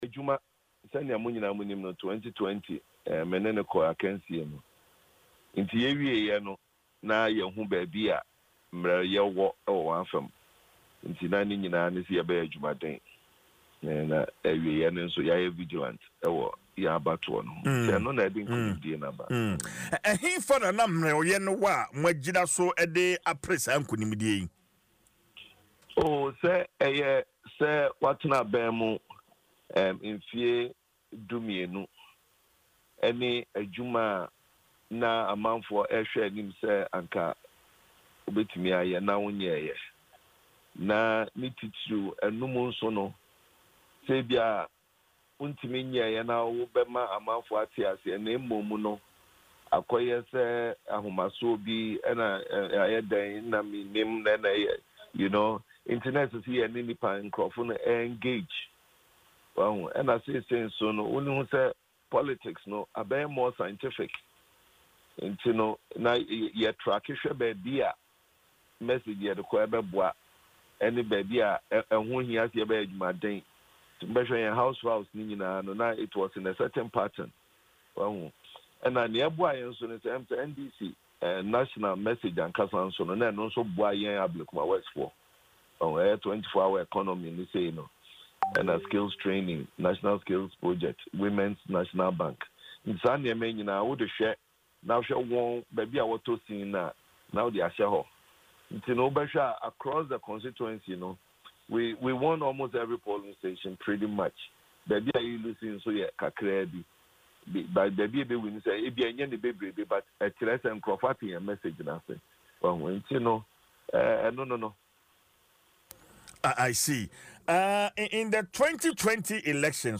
In an interview on Adom FM’s morning show, Dwaso Nsem he revealed that he was not surprised by his win, attributing it to several factors, including his opponent’s character.